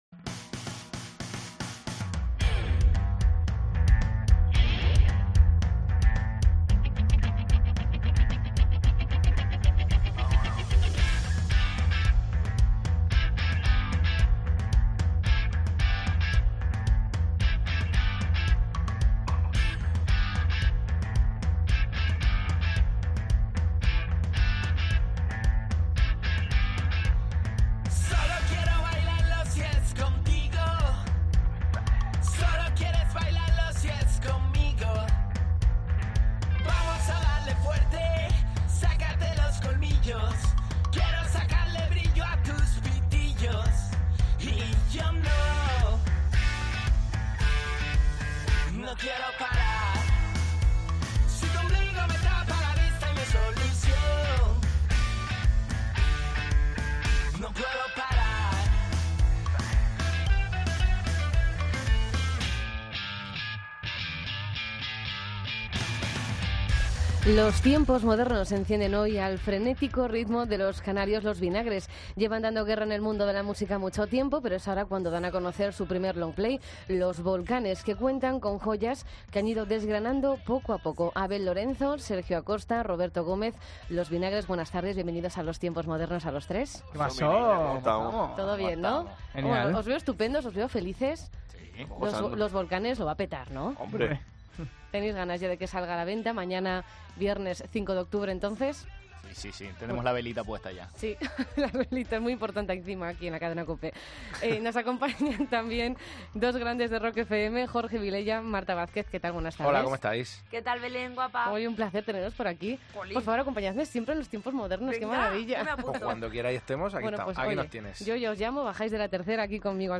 Entrevista a Los Vinagres